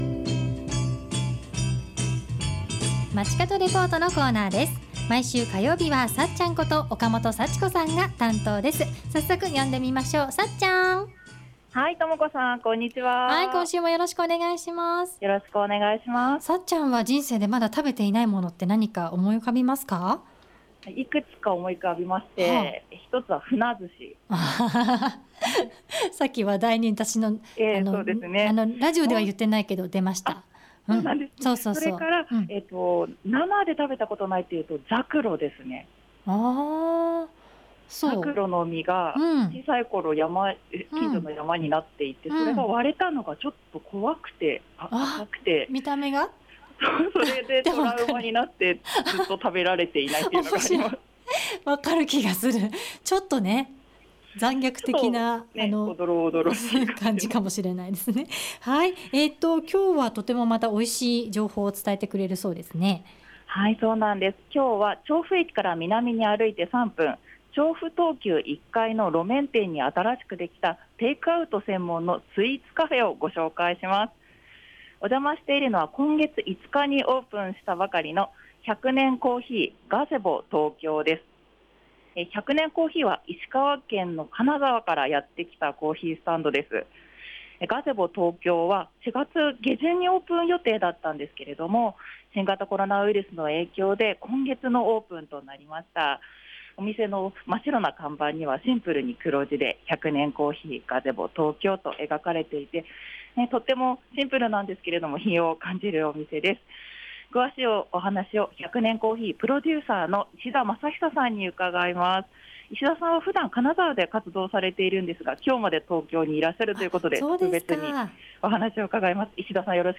午後のカフェテラス 街角レポート
中継は調布駅から南に歩いて3分。調布とうきゅう1Ｆの路面店に新しくできた テイクアウト専門のスイーツカフェ「百年珈琲 GAZEBO TOKYO」からお伝えしました♫ 石川県金沢からやって来たコーヒースタンドなんですよ。